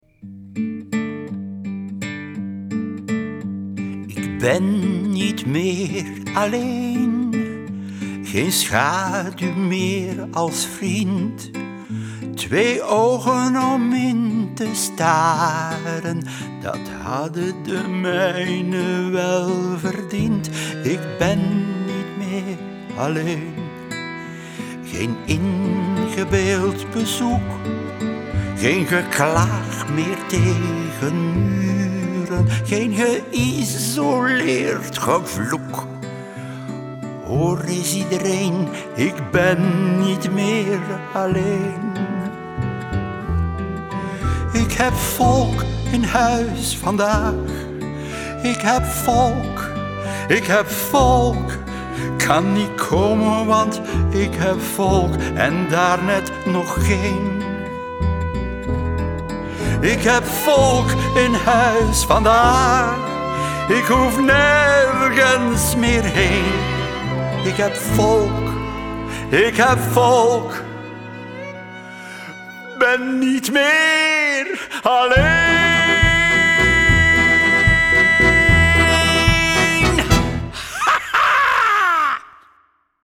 Met de stemmen van Warre Borgmans, Tine Embrechts, Gène Bervoets, Sien Eggers, Tom Van Dyck, Lynn Van Royen, Alex Agnew, Bob De Moor, Els Dottermans en Koen De Graeve.
Ze worden verteld, gespeeld en gezongen door Vlaanderens meest getalenteerde acteurs en zitten boordevol humor, liedjes en knotsgekke geluidseffecten.